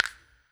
Bh Shaker.wav